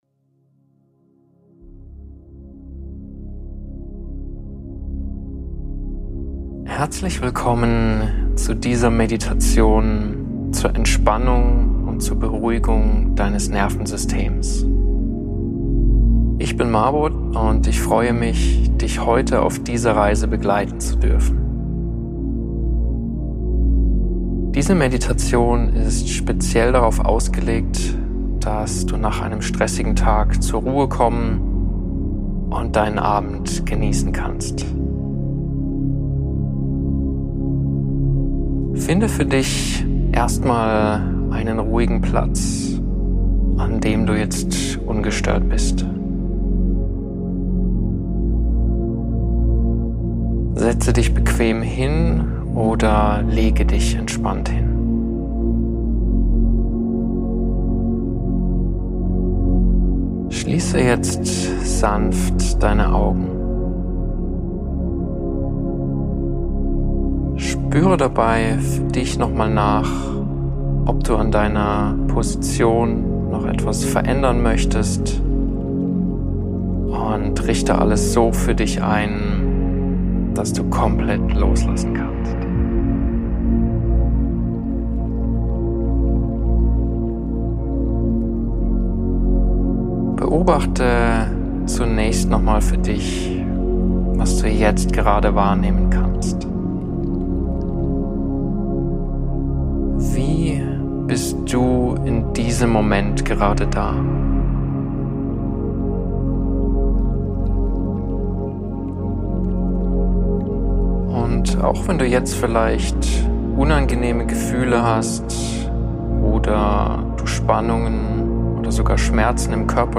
In dieser Episode leite ich dich durch eine Achtsamkeitsmeditation, um dein Gedankenkarussell immer weiter zu beruhigen und besser abschalten zu können.